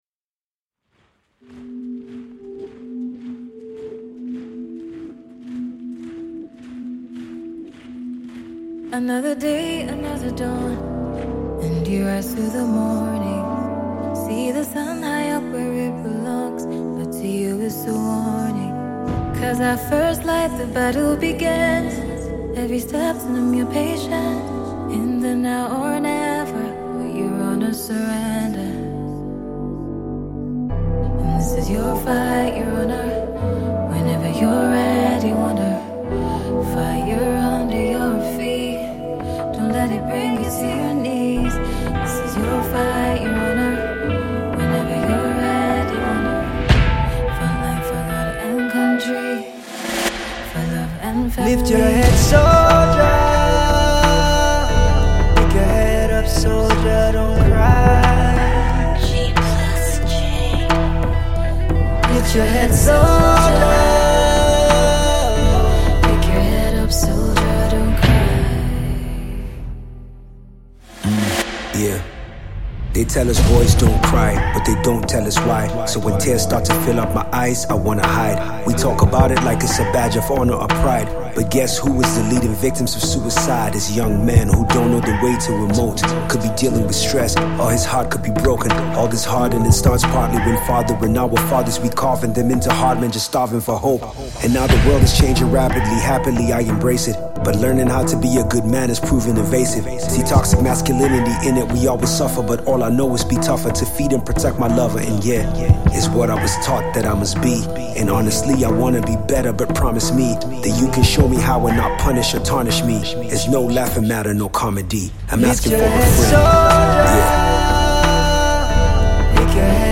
a multi-talented Nigerian rapper
a Nigerian female singer-songwriter